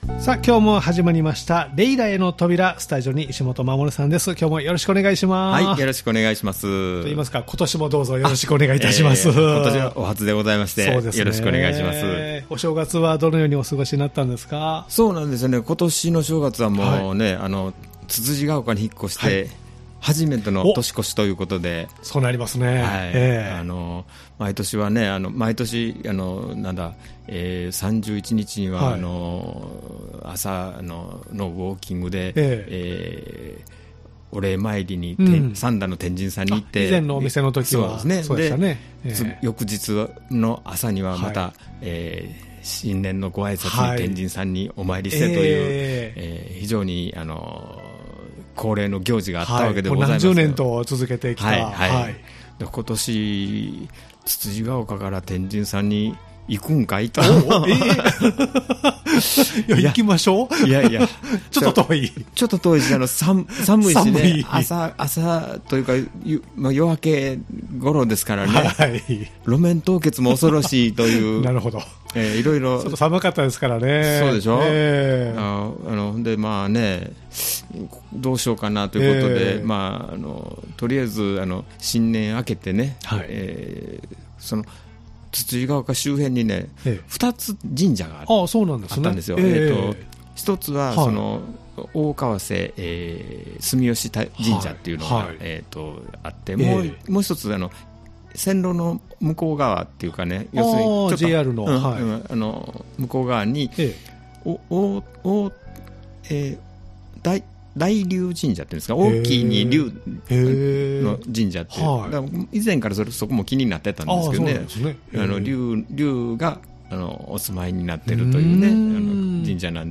ということで、今月は年末年始の様子、エリック・クラプトン シグネチャー30周年記念モデルMartin 000-EC 30tｈ ANNIVERSARYの最終入荷分情報など盛りだくさんのゆるゆるトークでお送りします。